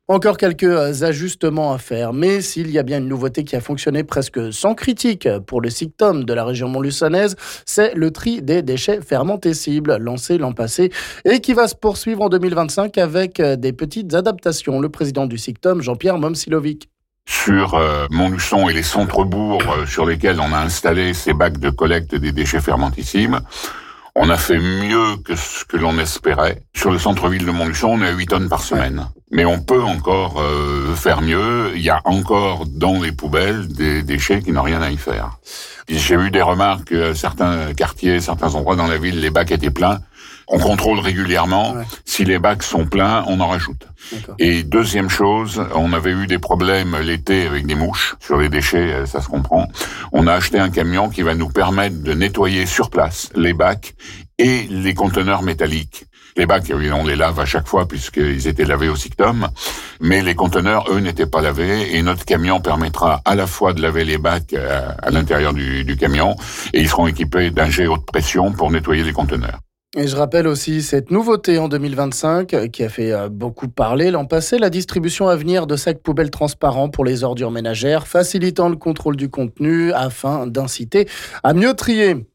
Le président du SICTOM Jean-Pierre Momcilovic nous en parle...